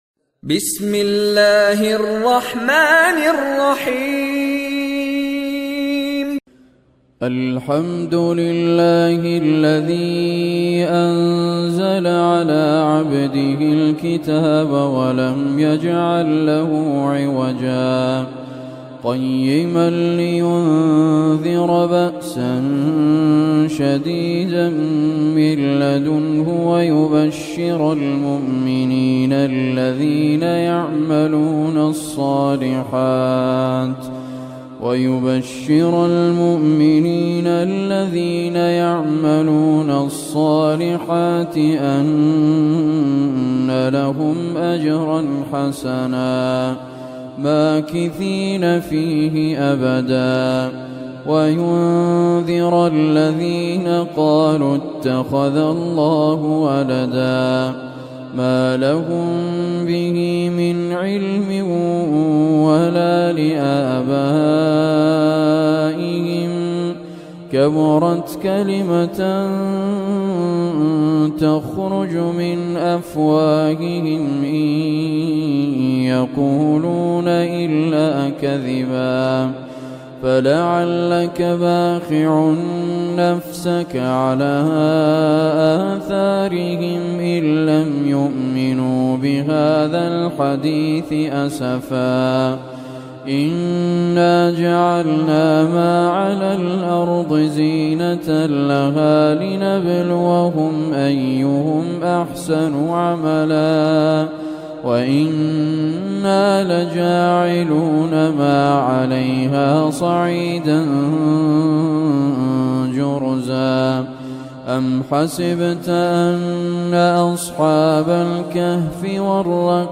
Surah Kahf Recitation
Surah Al Kahf, listen or play online mp3 tilawat / recitation